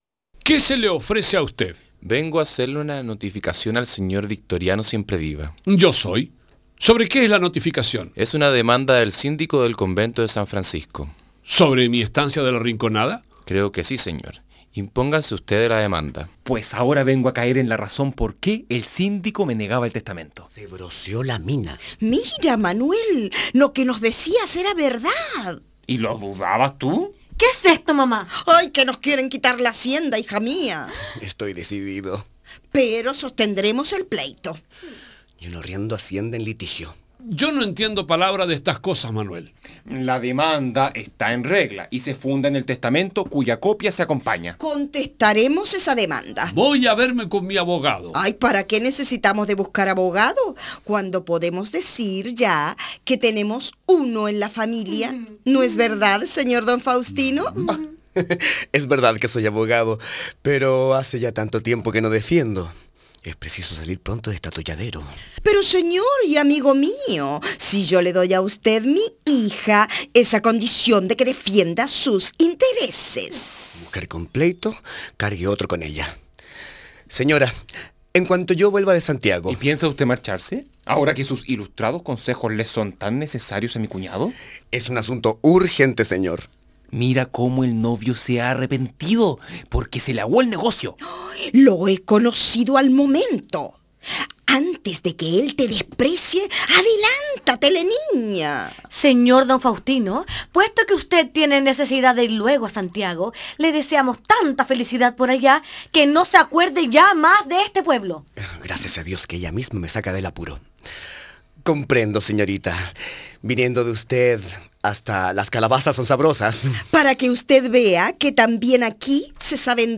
Como en Santiago – Lecturas dramatizadas